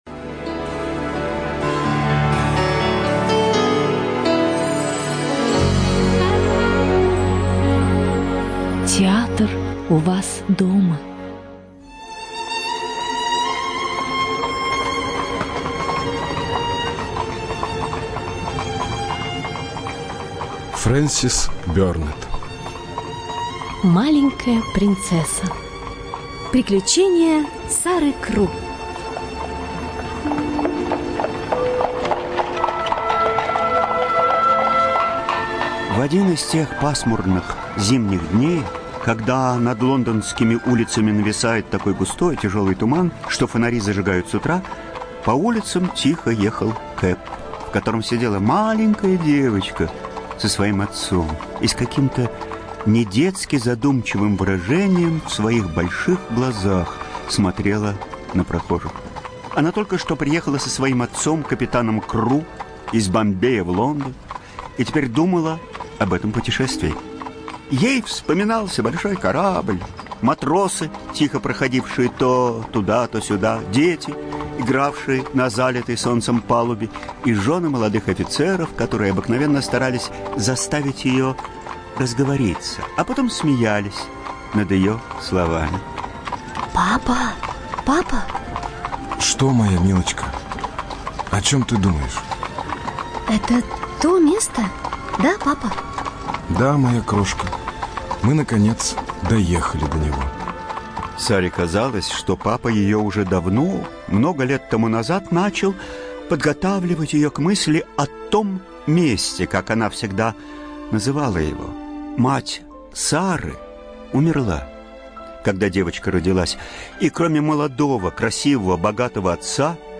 ЖанрДетский радиоспектакль